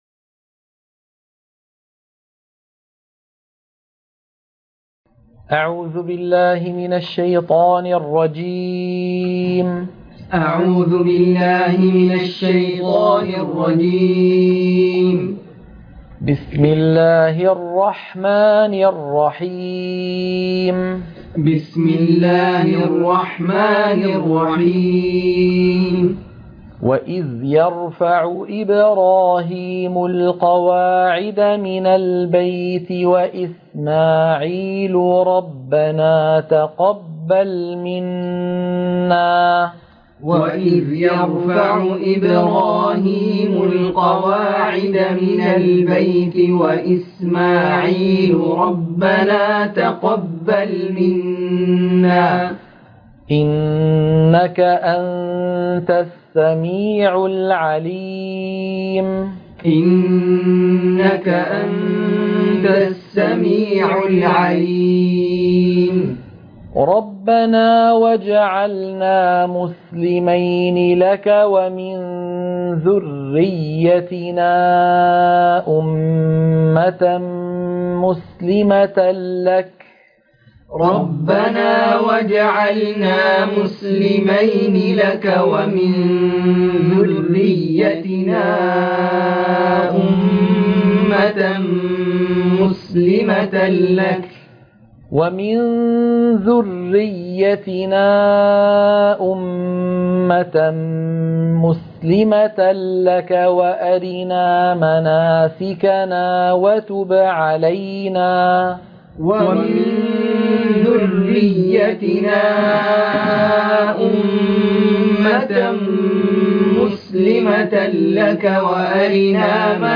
عنوان المادة تلقين سورة البقرة - الصفحة 20 _ التلاوة المنهجية